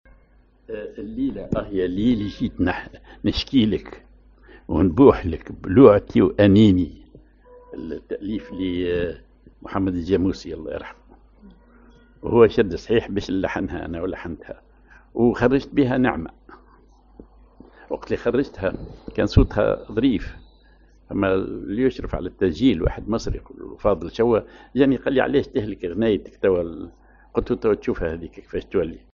Maqam ar صبا
Rhythm ar الوحدة
genre أغنية